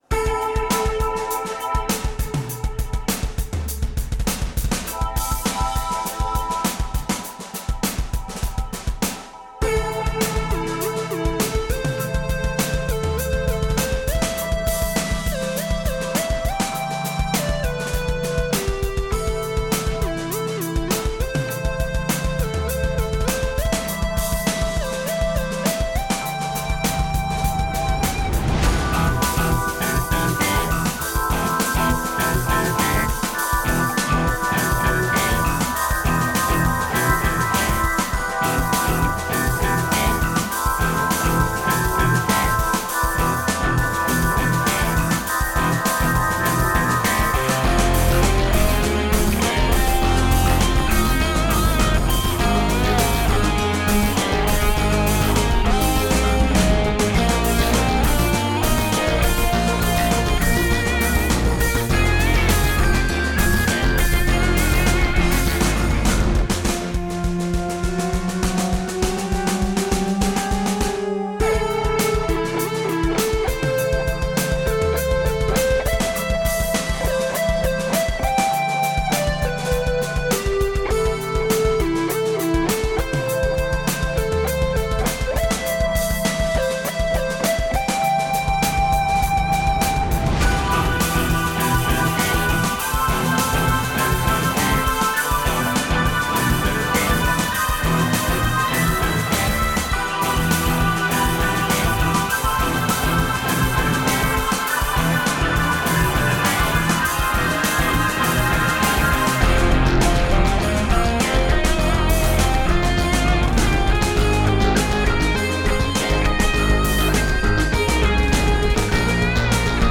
Bass is my JayDee Roadie II; synths are all Korg M3.
Stormy, strong and tight!
Great use of space in the mix.